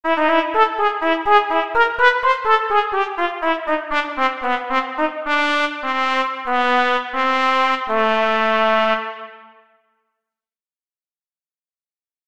Fraseo de trompeta